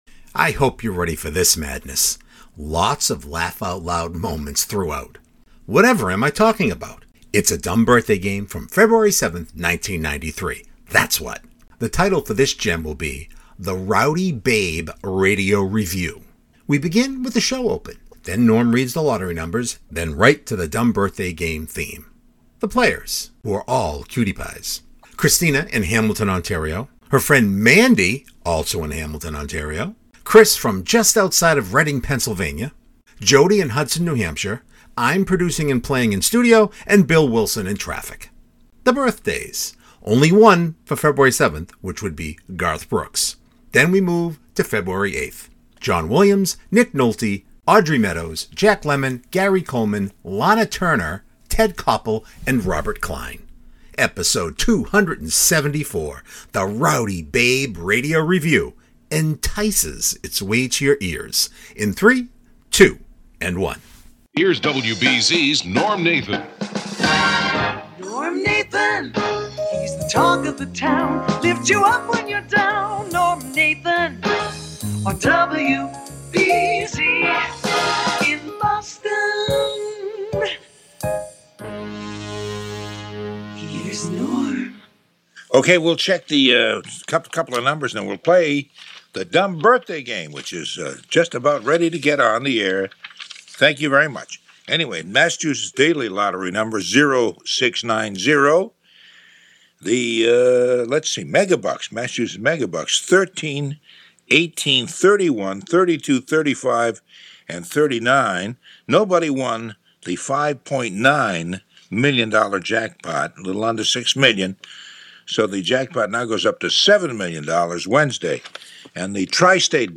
Lots of laugh out loud moments throughout.